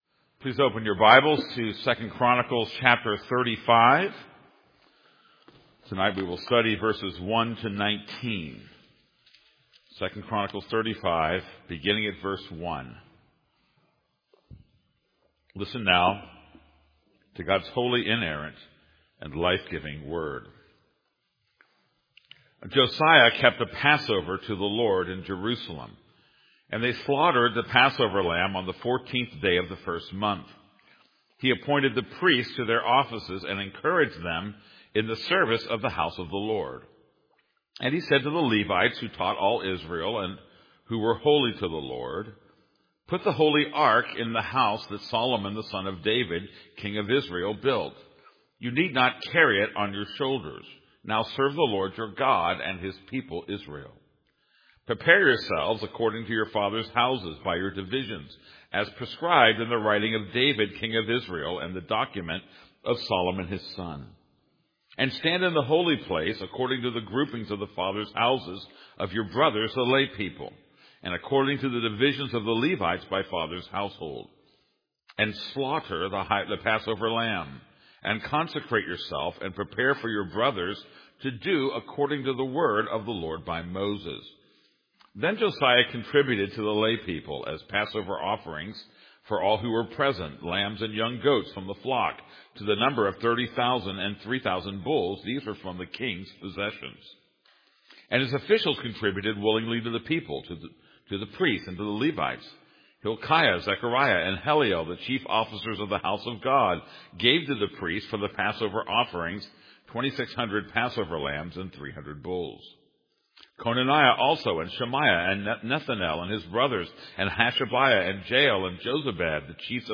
This is a sermon on 2 Chronicles 35:1-27.